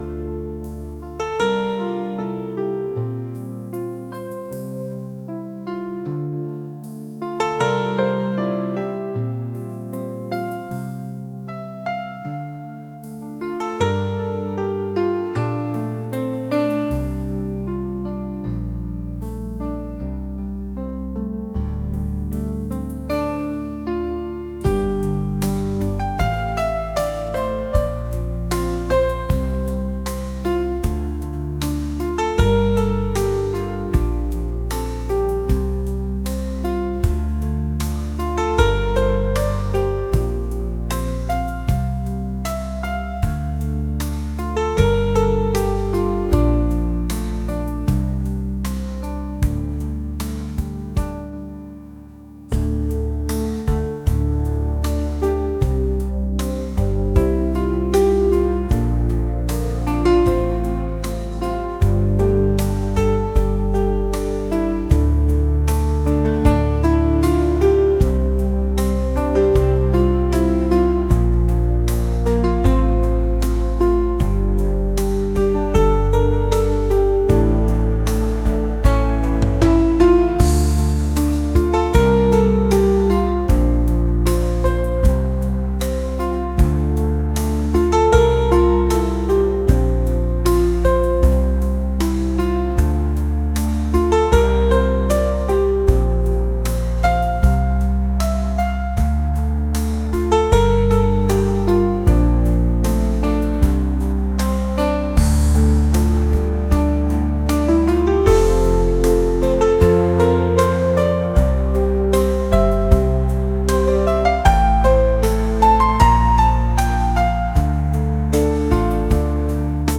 ambient | cinematic | pop